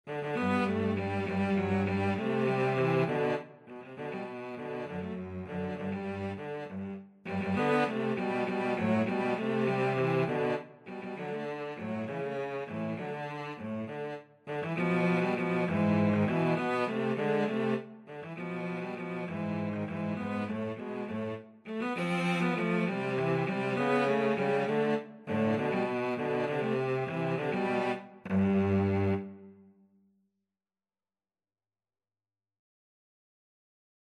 3/8 (View more 3/8 Music)
Cello Duet  (View more Easy Cello Duet Music)
Classical (View more Classical Cello Duet Music)